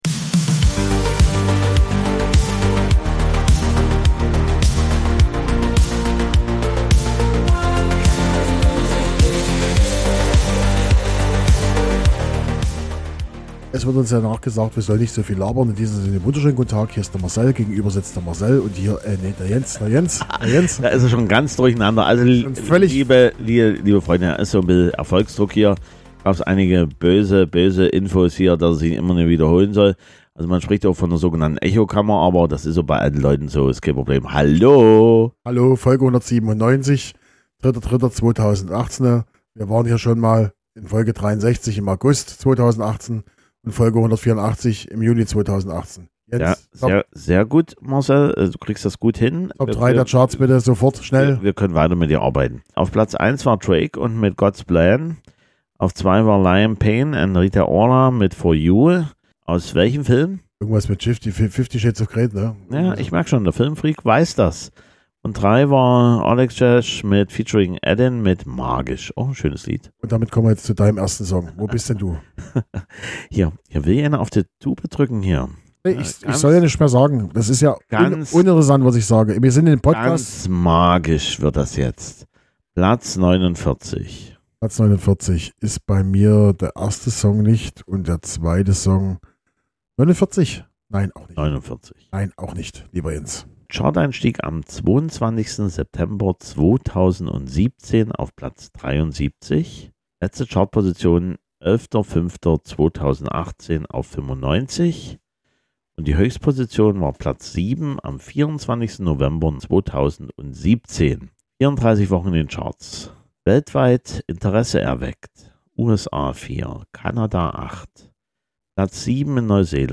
Bunte Mischung verpackt in einer depressiven Stimmung.